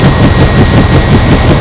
Convert stereo files to mono
helicopter.wav